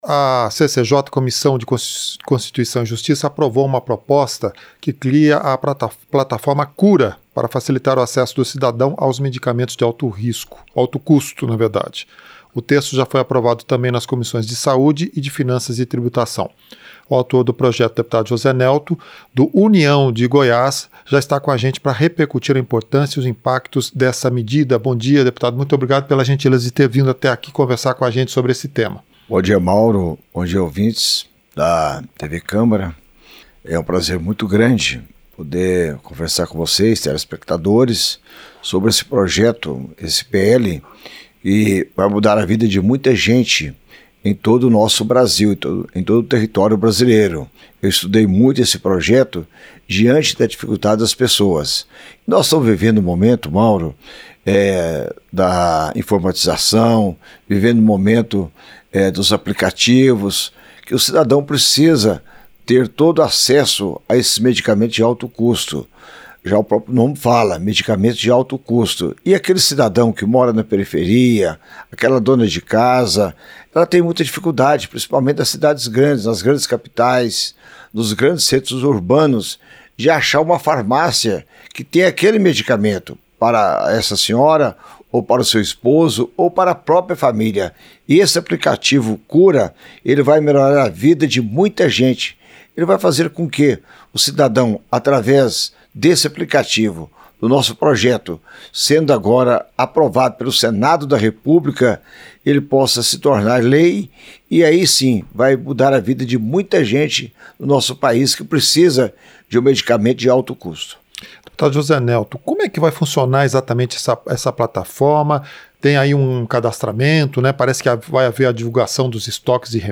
Entrevista - Dep. José Nelto (União-GO)